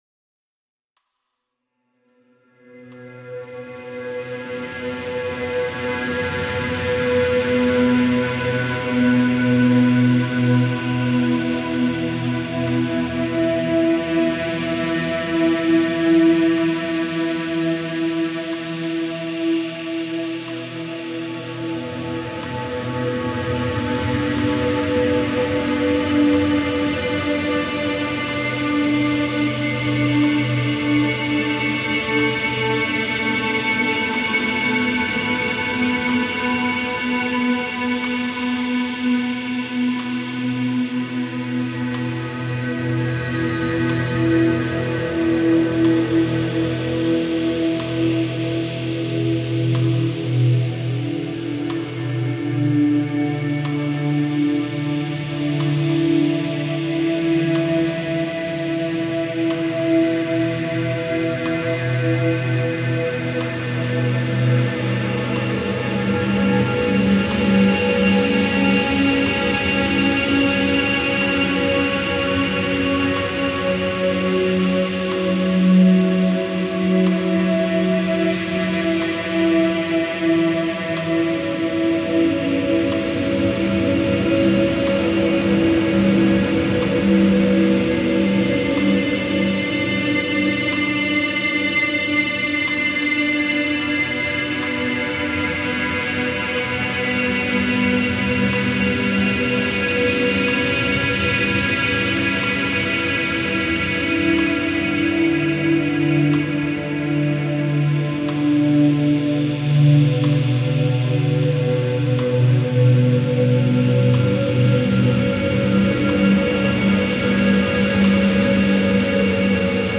a discrete drum beat and chimes.